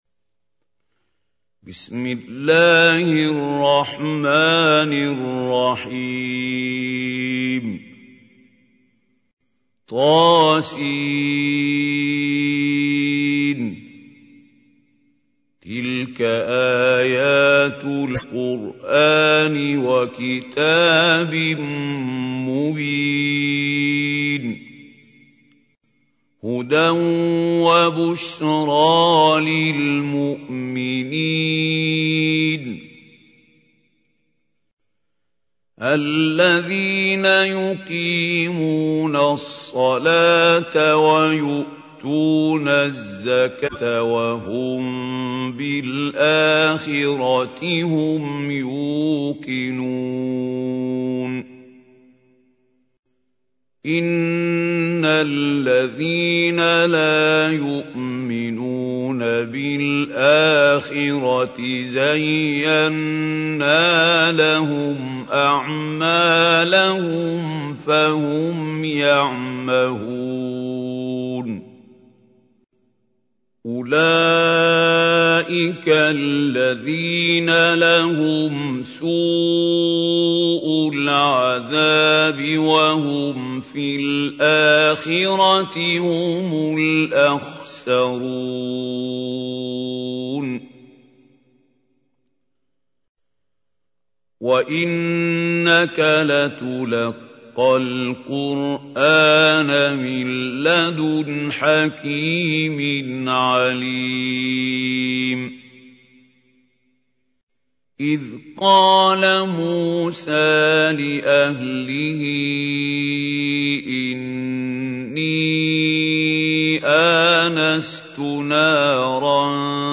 تحميل : 27. سورة النمل / القارئ محمود خليل الحصري / القرآن الكريم / موقع يا حسين